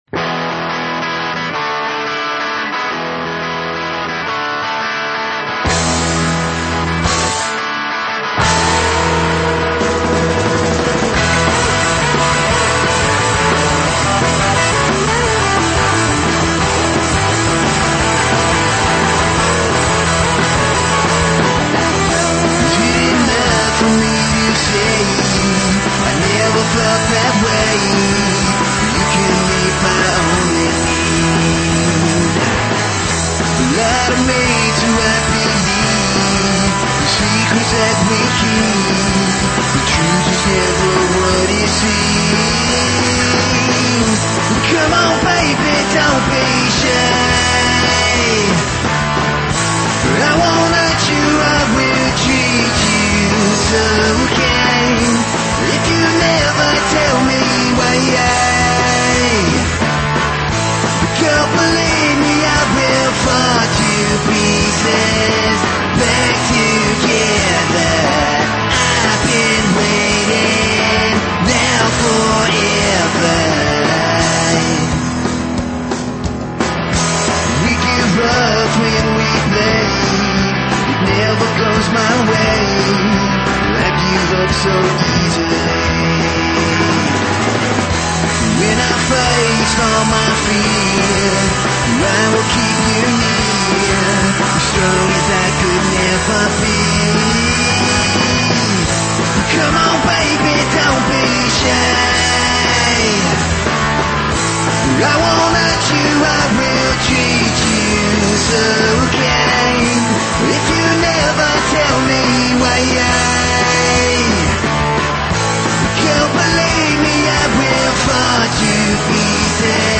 rock
punk
metal
high energy rock and roll